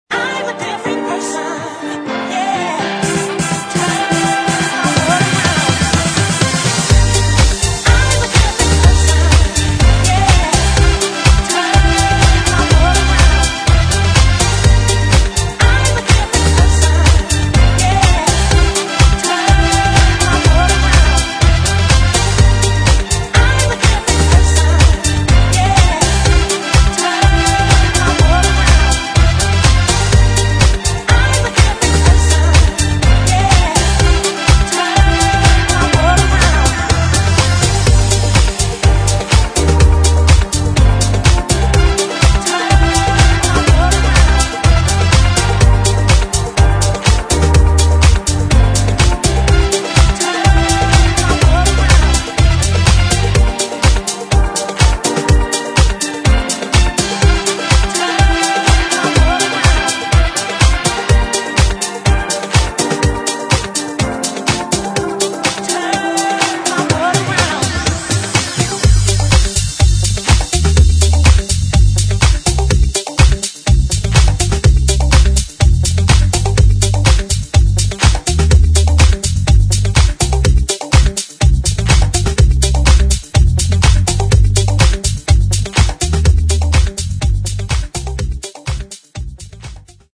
[ DISCO / HOUSE ]